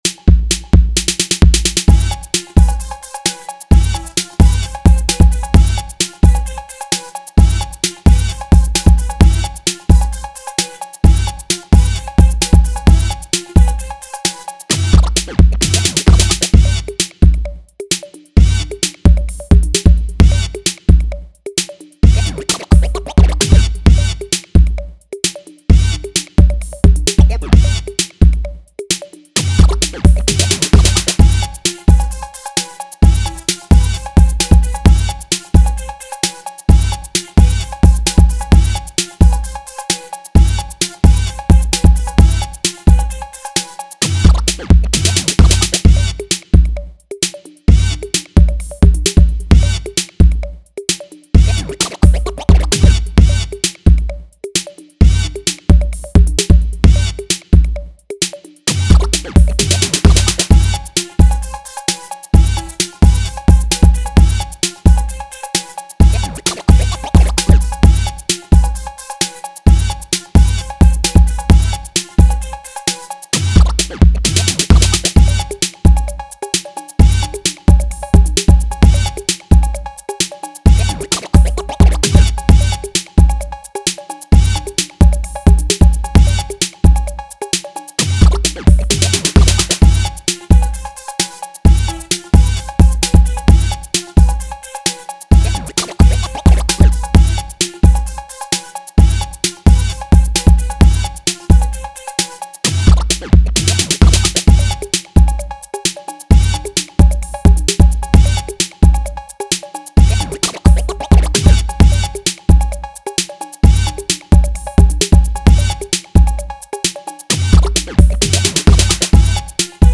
80s, Dance